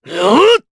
Clause_ice-Vox_Attack4_jp.wav